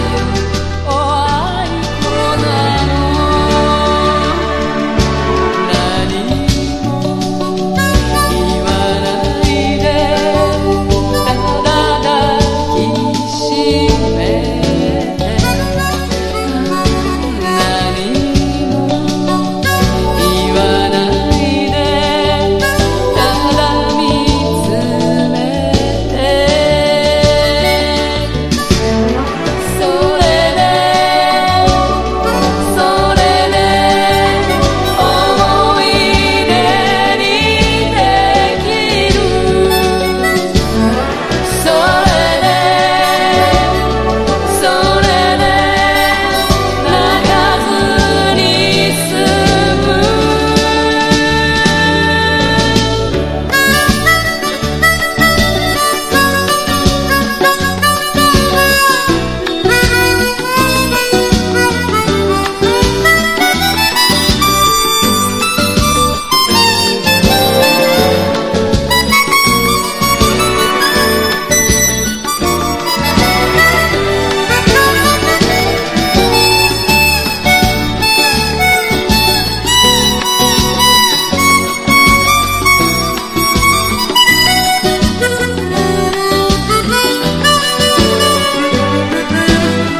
哀愁度がさらに増してます。
SSW / FOLK